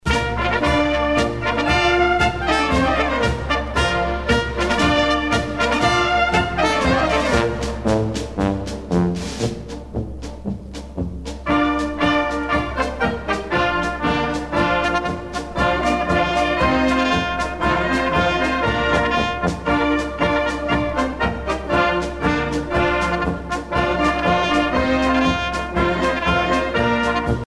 à marcher
Pièce musicale éditée